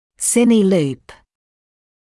[‘sɪnɪ luːp][‘сини луːп]кинопетля; видеопетля